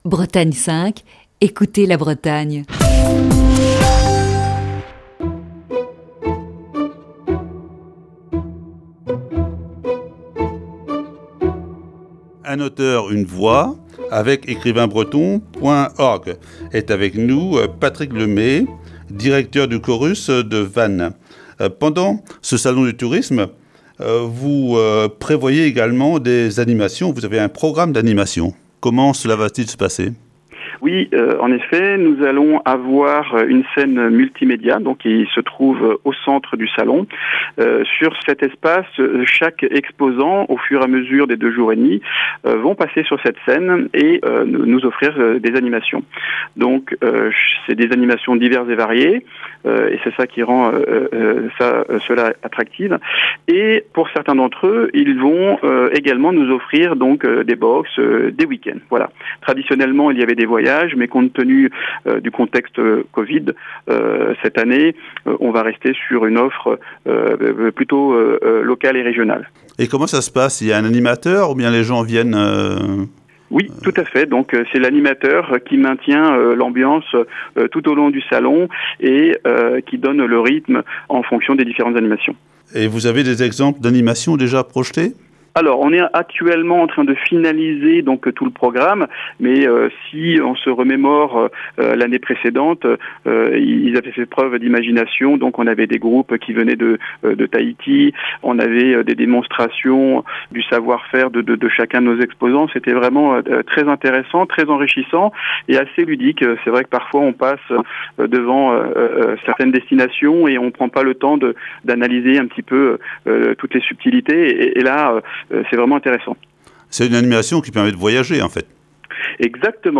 Ce mardi, deuxième partie de cet entretien